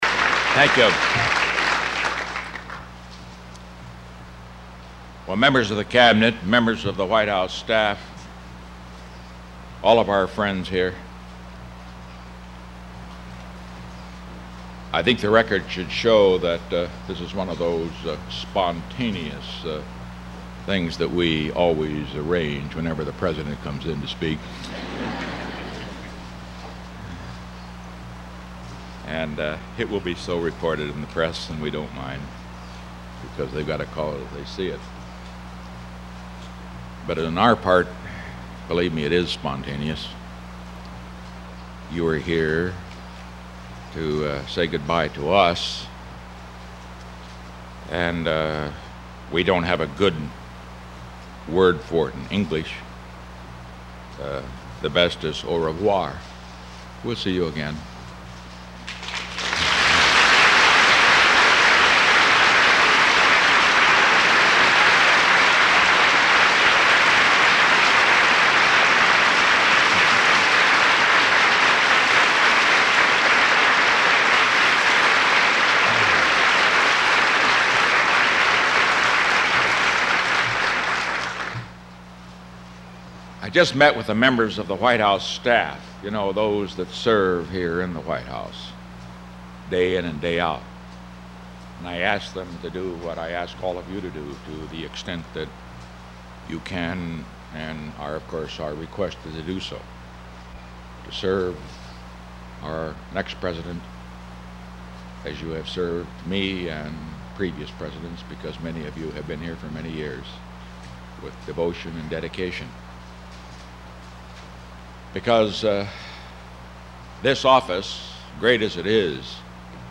August 9, 1974: Remarks on Departure From the White House